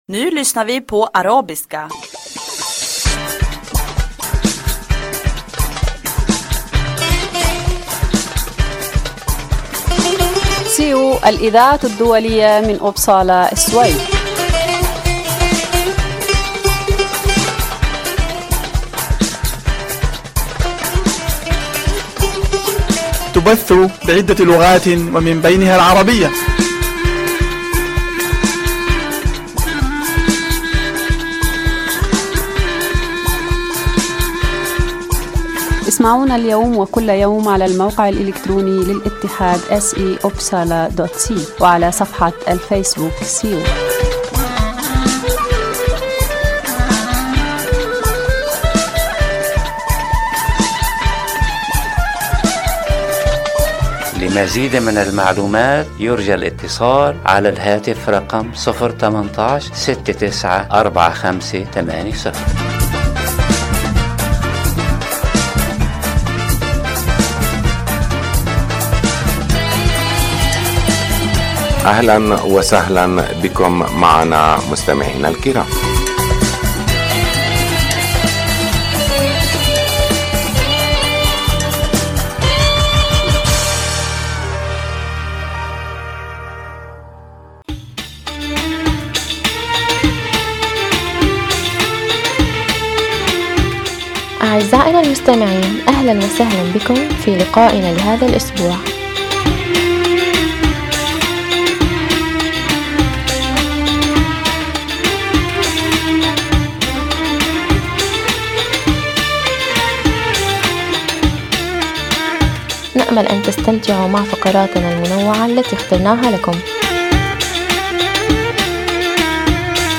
يتضمن البرنامج أخبار من مدينة إبسالا و السويد تهم المهاجرين و برامج ترفيهية و مفيدة أخرى. برنامج هذا الأسبوع يتضمن أخبار الاتحاد السيو، من أخبارنا المحلية و مقتطفات من الصحف العربية و أخبار متنوعة و من الشعر و الموسيقى .